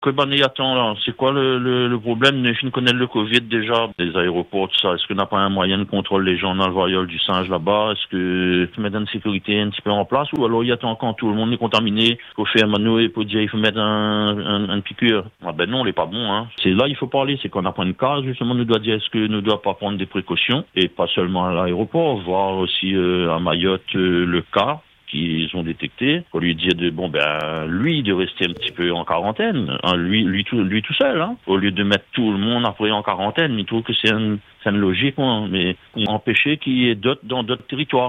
Sur notre antenne, un auditeur s’interroge ouvertement :